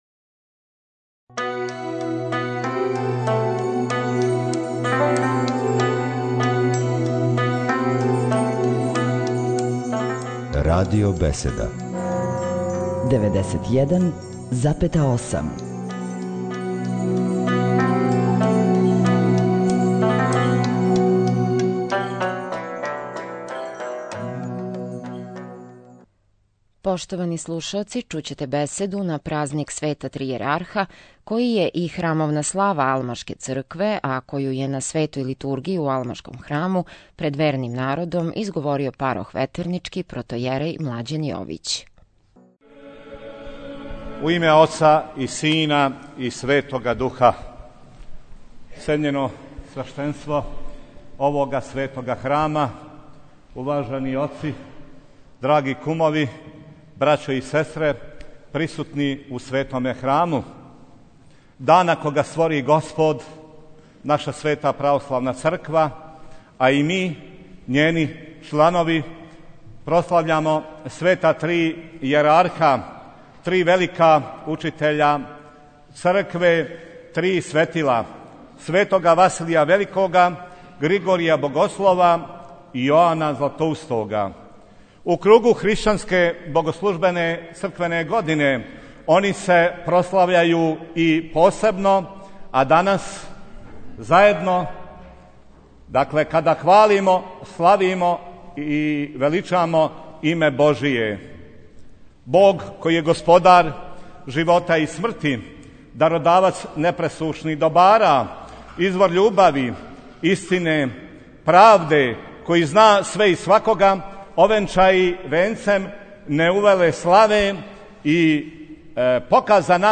На празник Света Три Јерарха, у суботу 12. фебруара 2011. године, житељи Новог Сада прославили су славу Aлмашког храма.